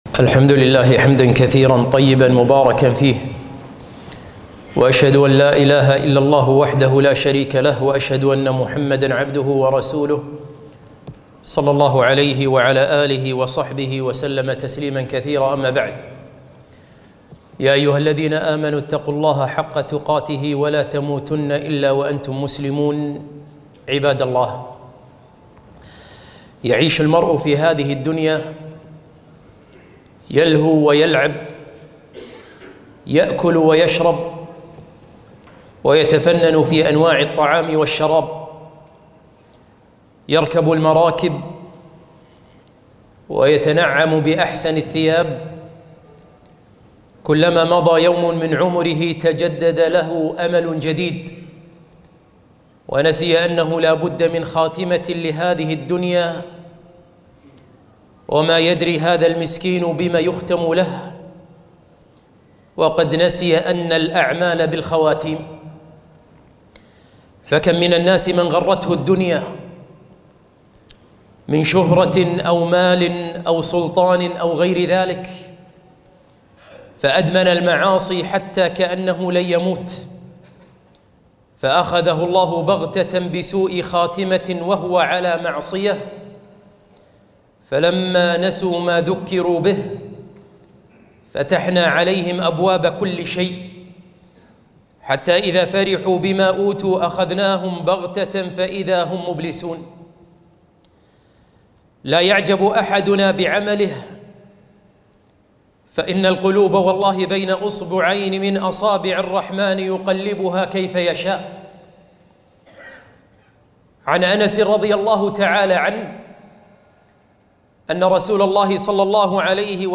خطبة - ما الذي أقلق الصالحين؟